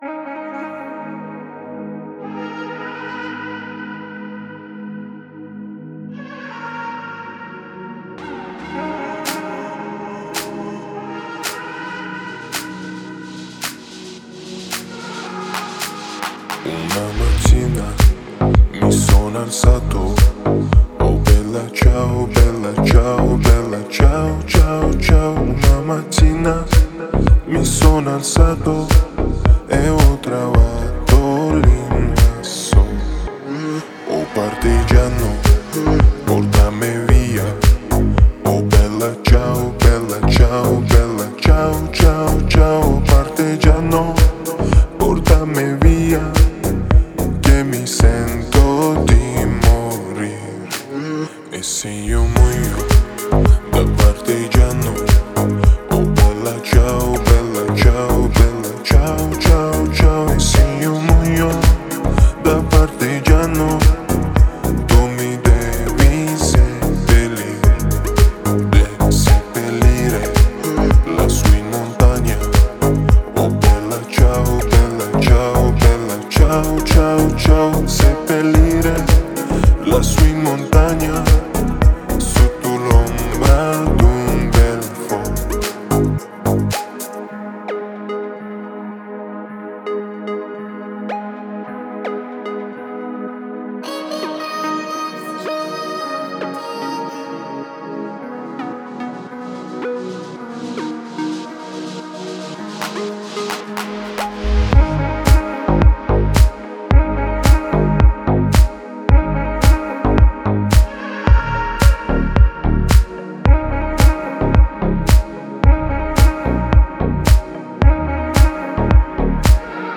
Спокойная музыка
спокойная музыка